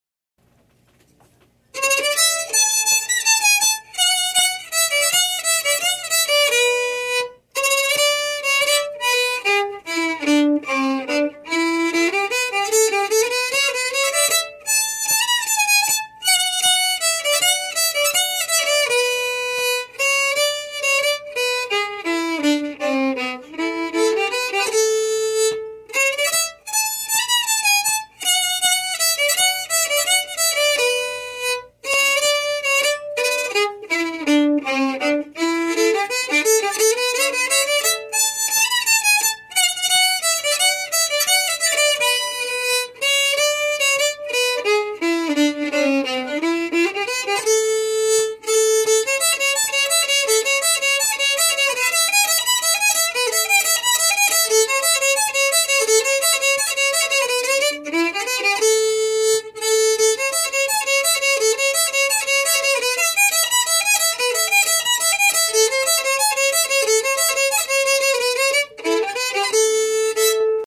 Key: A
Form: March
Region: Sweden